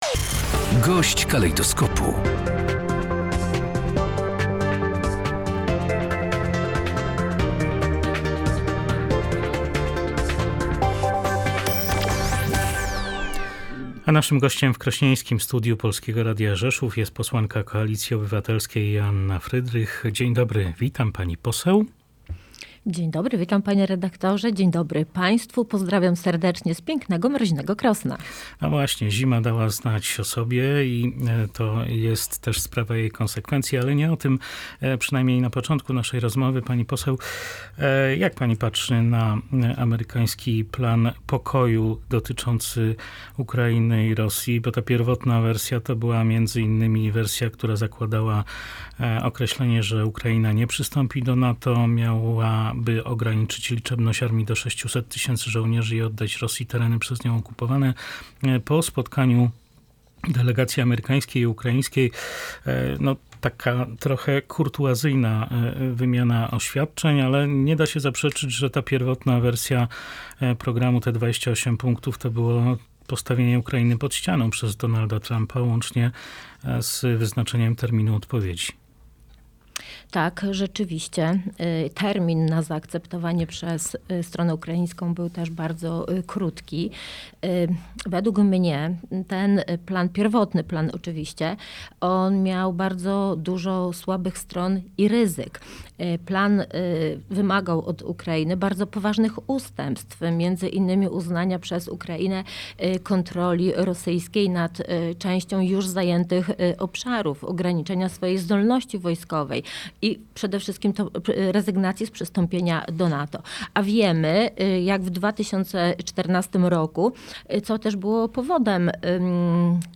W rozmowie z Polskim Radiem Rzeszów podkreśliła, że plan nie był konsultowany z UE i NATO i mógł prowadzić do destabilizacji oraz legitymizacji działań Rosji.